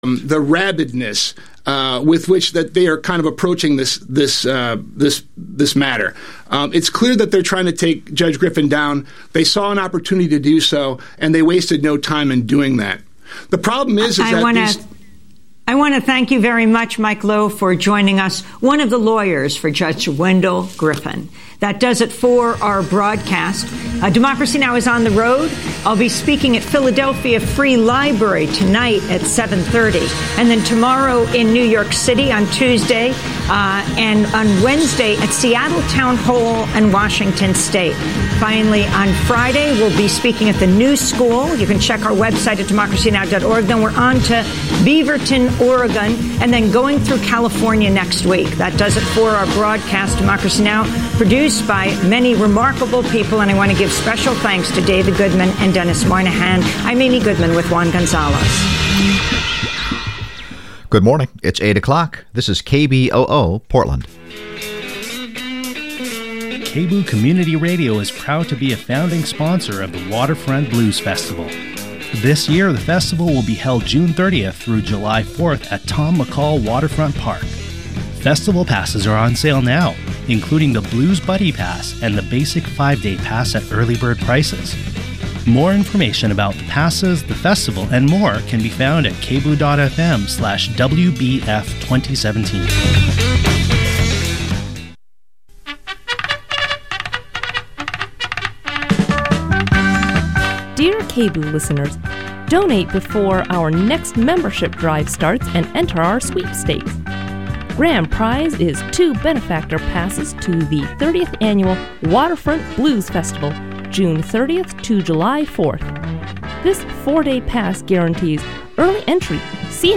Program:: More Talk Radio